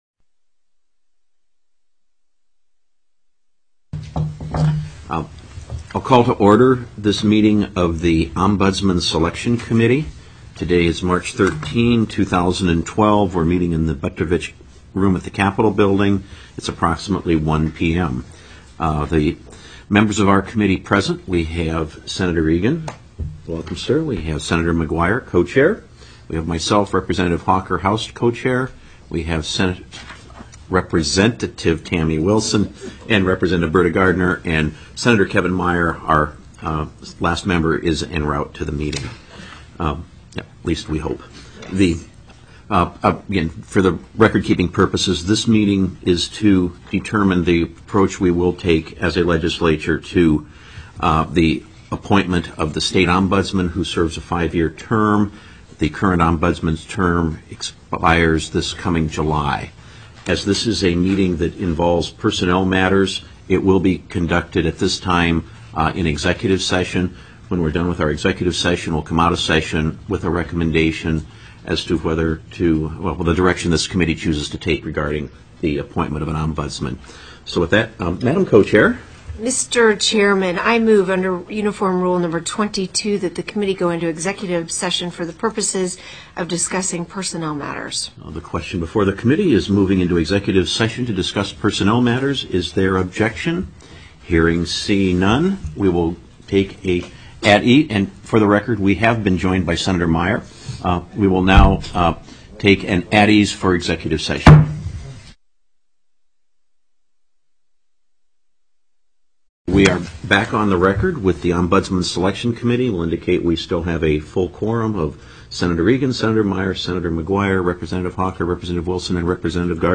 03/13/2012 01:00 PM Senate OMBUDSMAN SELECTION COMMITTEE
+ teleconferenced
CO-CHAIR  MIKE  HAWKER  called   the  Joint  Ombudsman  Selection